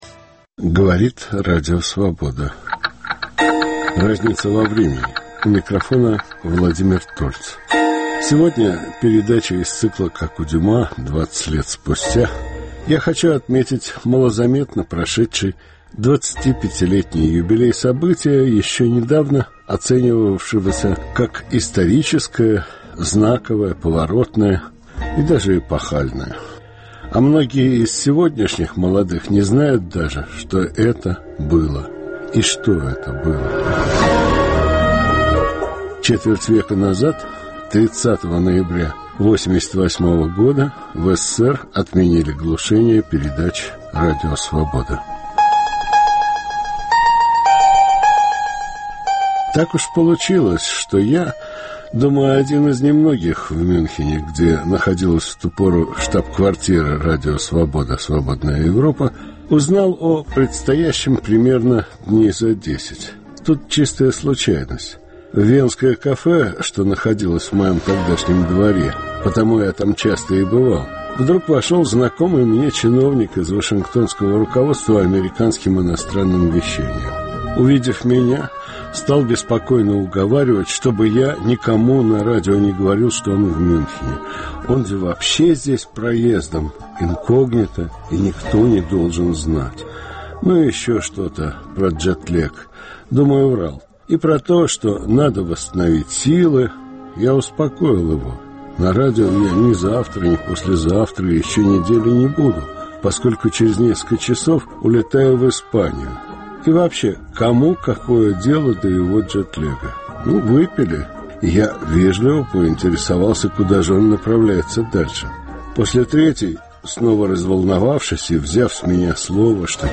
Одна из непреложных истин: без знания вчерашнего дня нельзя понять сегодняшнего. Ведущий